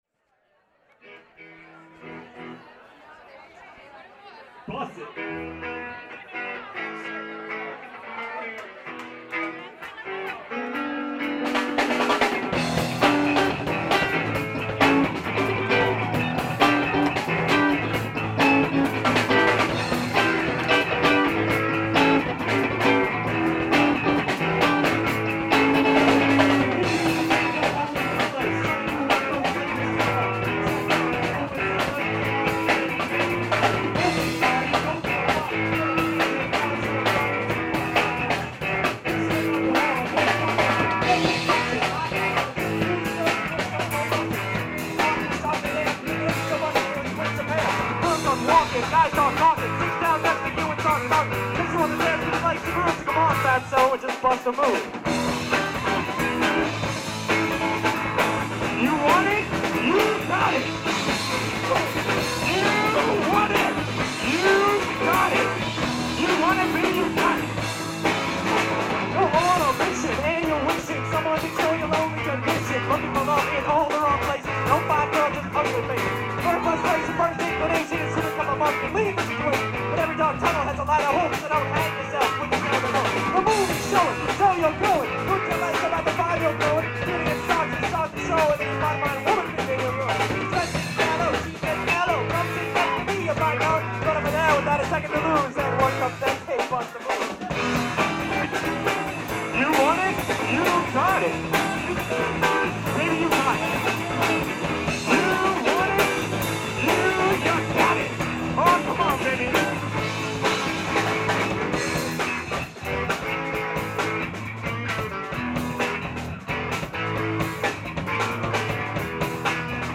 Bass
Lead Guitar, Vocals
Drums
Live at the Underground (10/13/95) [Entire Show]